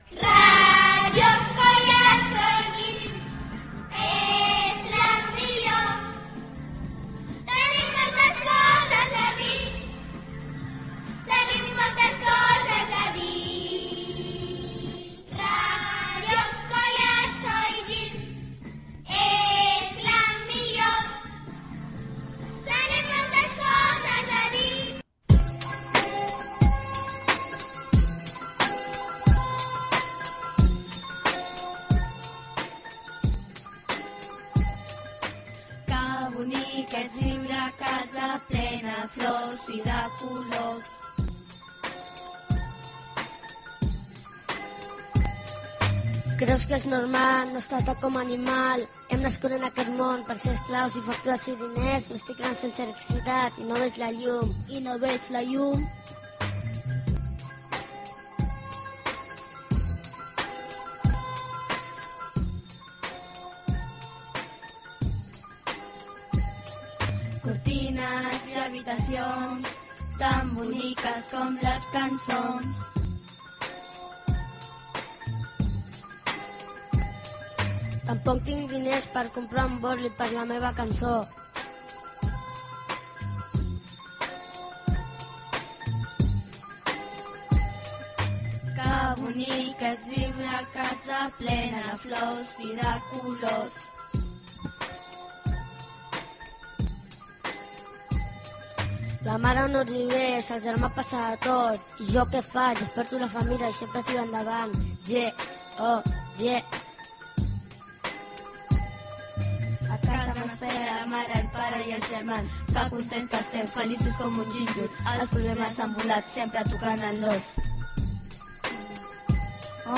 Indicatiu cantat de la ràdio, rap cantat per l'alumnat.